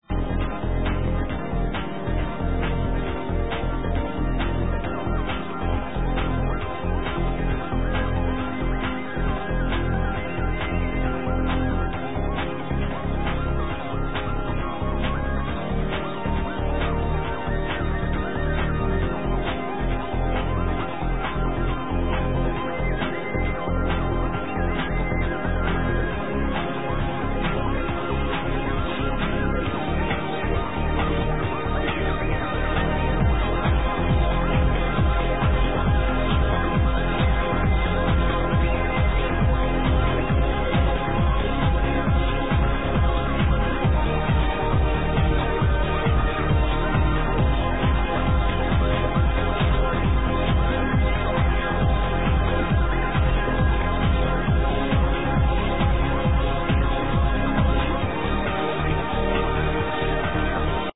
Ok this song is from the warmup set.
It's really relax